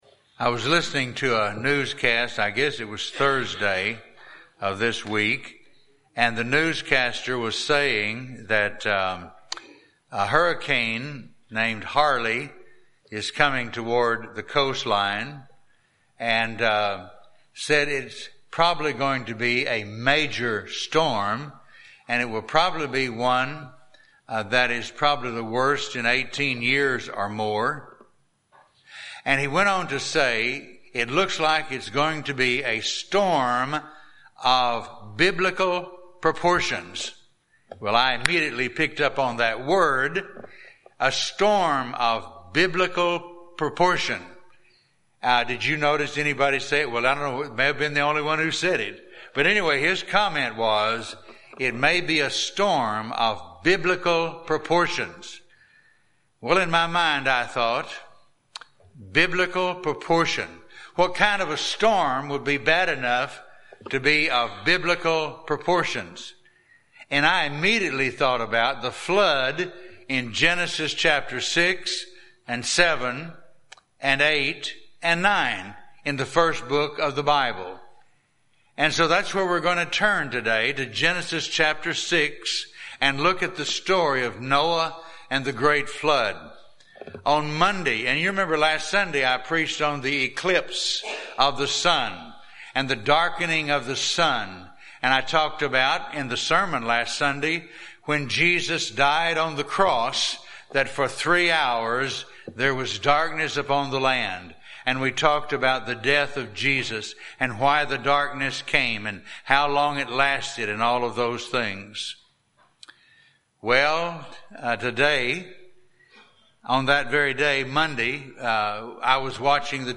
Morning message from Genesis 6-7